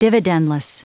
Prononciation du mot dividendless en anglais (fichier audio)
Prononciation du mot : dividendless